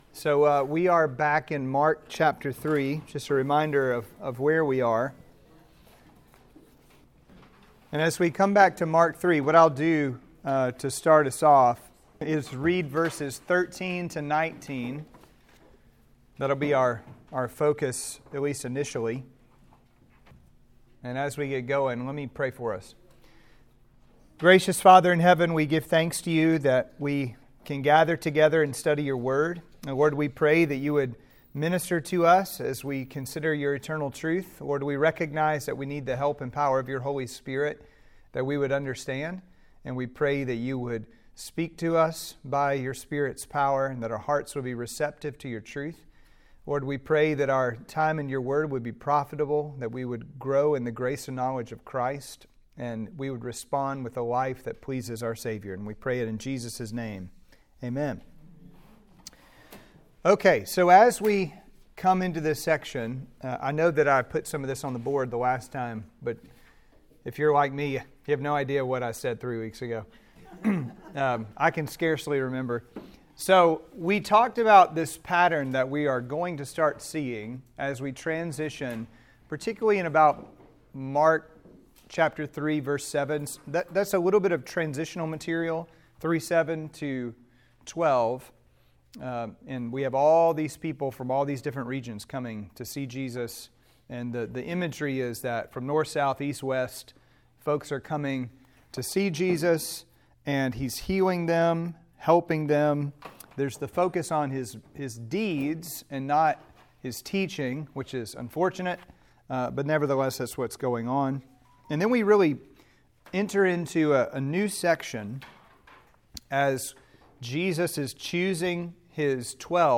Sermons & Sunday School Series « » The Fall 2M ago ➕ Подписаться ➕ Подп.